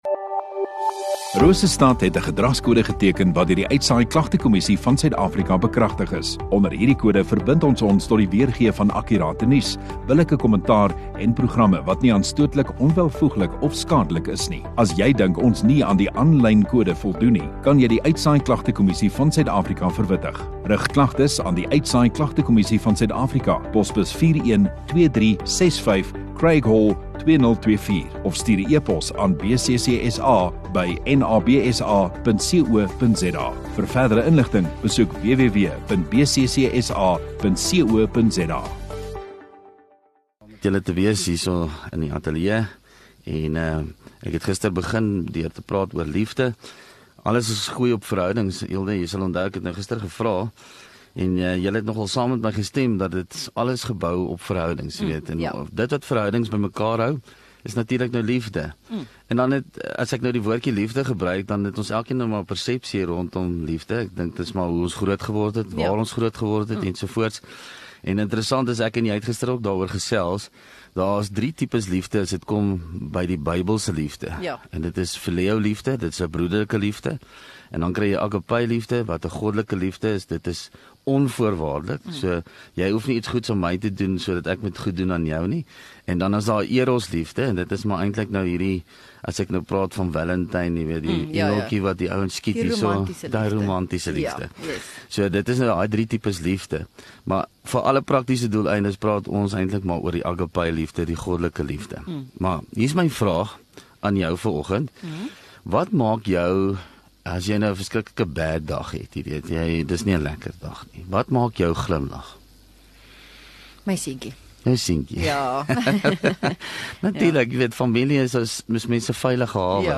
16 Jul Dinsdag Oggenddiens